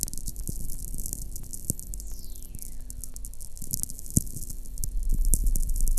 Heidelberg, Germany 49.443N,8.695E